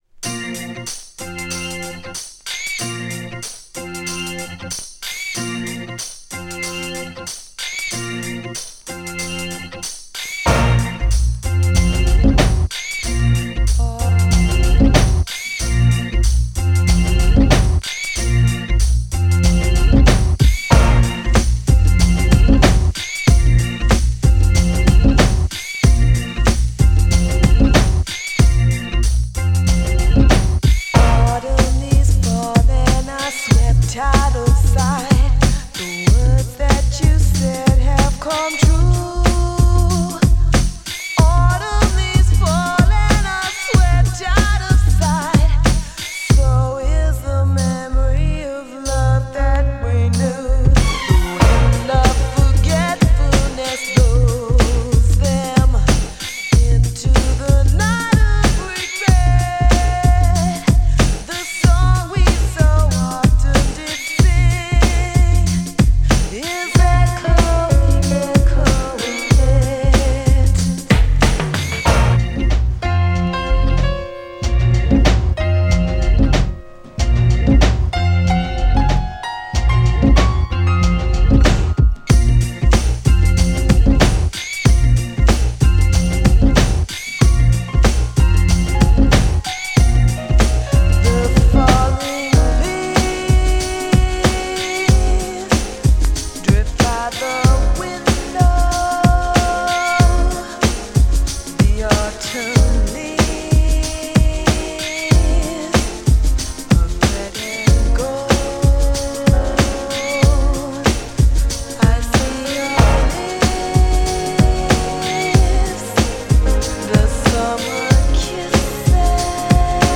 美しく力強い
グランドビートのMIXと、アンビエントでエキゾチックでDEEPなA2のMIX両方すごいです!!
GENRE R&B
BPM 91〜95BPM